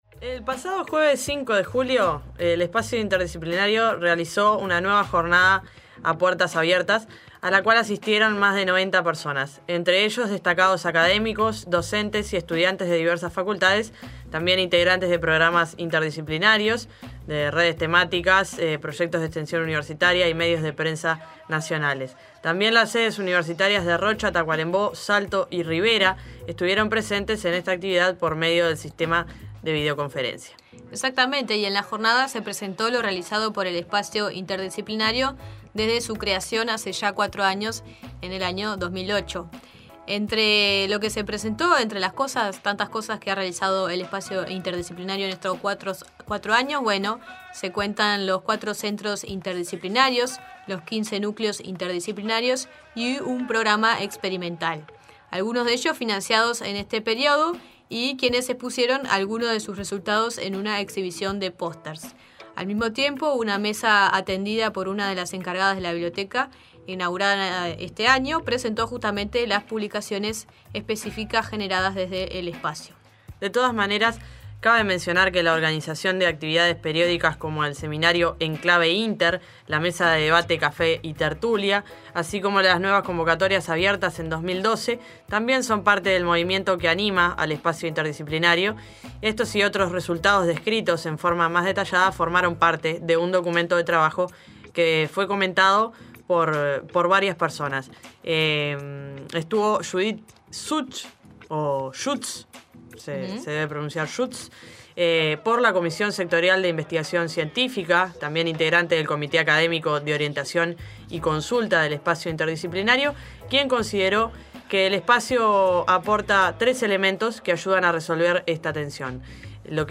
Podcast: Espacio Interdisciplinario de puertas abiertas, entrevista